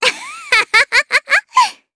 Cecilia-Vox_Happy3_jp.wav